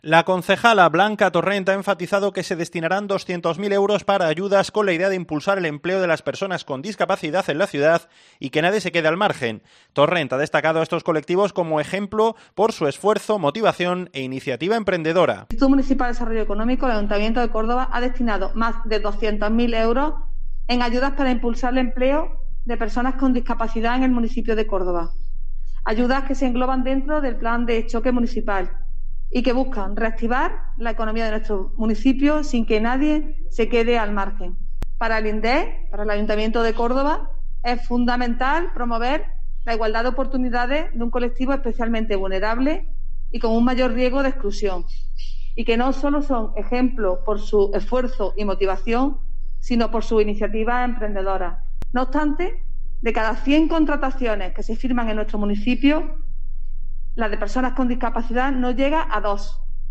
En una rueda de prensa, la concejal ha subrayado que "para el Imdeec y el Ayuntamiento es fundamental promover la igualdad de oportunidades de un colectivo especialmente vulnerable y como mayor riesgo de exclusión, y que no sólo son ejemplo por su esfuerzo y motivación, sino por su iniciativa emprendedora".